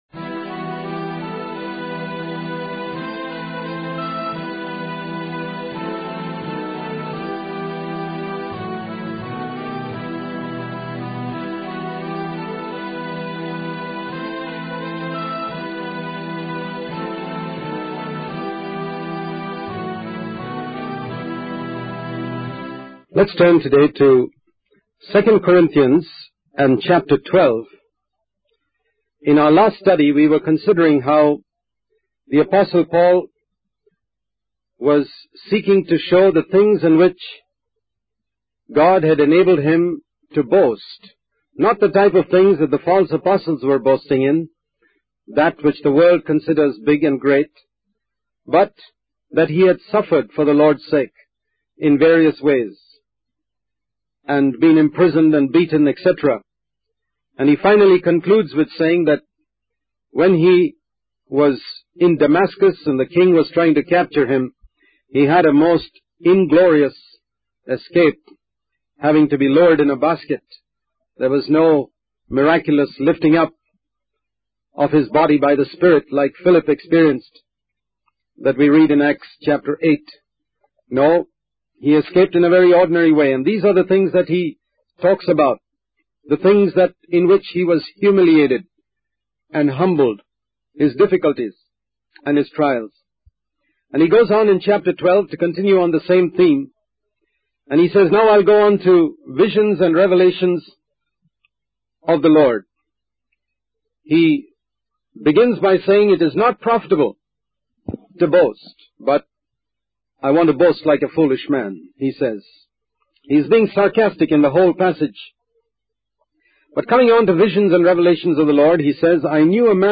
In this sermon, the Apostle Paul addresses the Corinthians and emphasizes that he will not show leniency when dealing with sin in their midst.